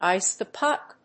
アクセントíce the púck